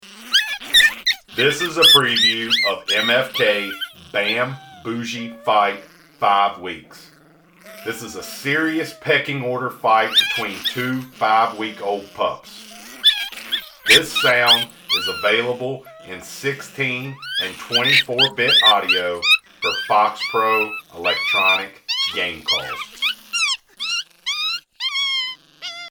quality wildlife audio available to hunters in today’s market.
The Big Difference- Our one-of-a-kind live coyote library naturally recorded at extremely close
range from our very own hand raised, free range coyotes sets MFK apart from all other libraries.